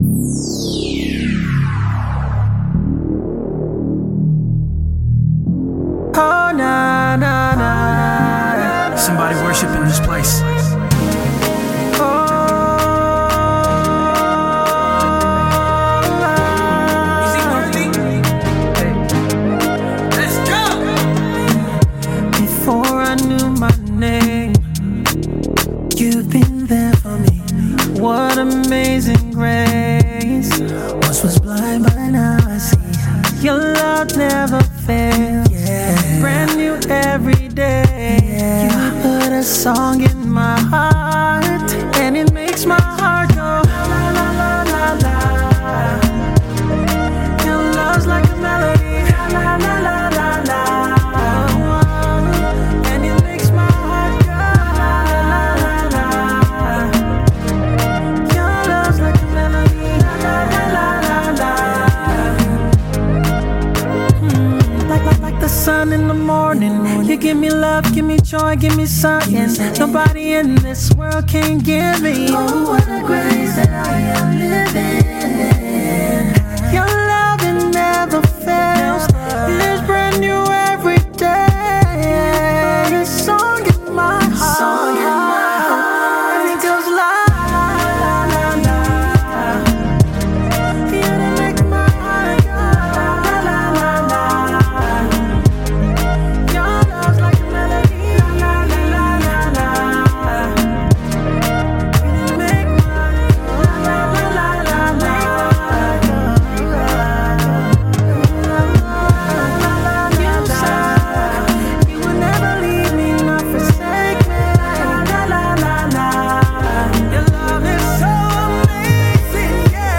inspirational tune